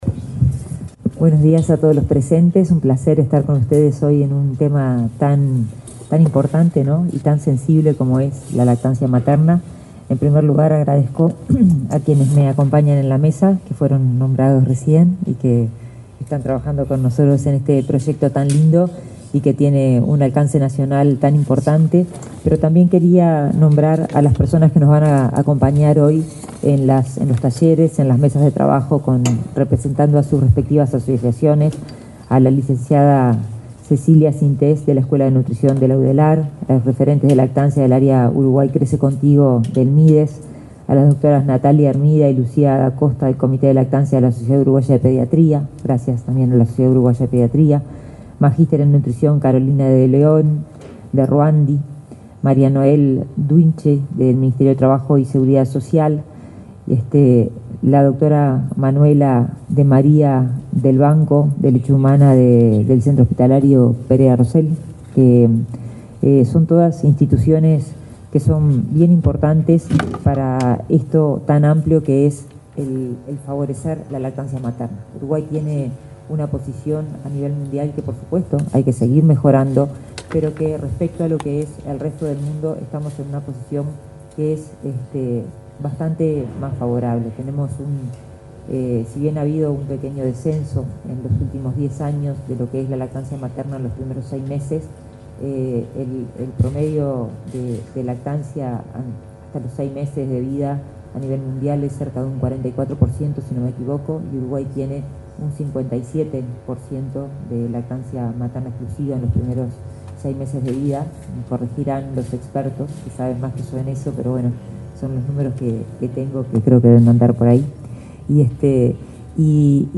Palabras de la ministra de Salud Pública, Karina Rando
La ministra de Salud Pública, Karina Rando, participó, este jueves 1.° en la sede central de su cartera, al acto por la Semana Mundial de la Lactancia